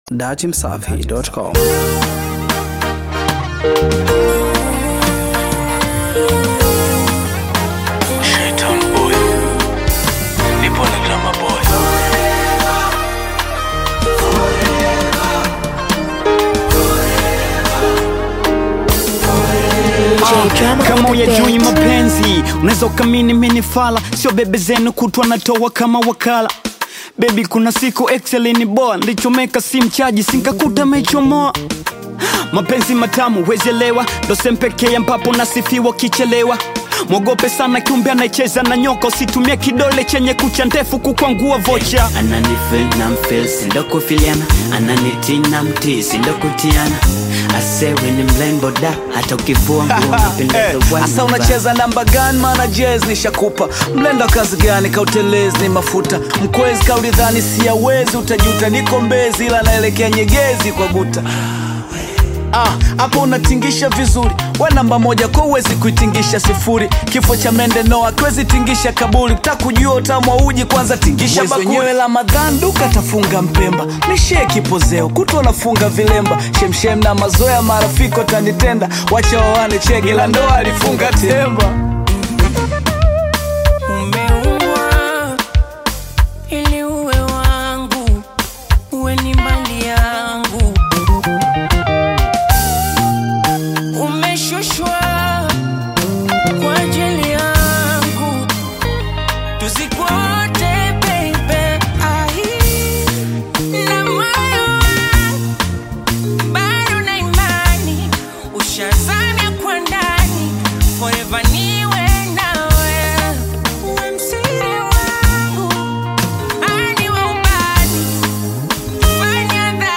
Bongo Flavour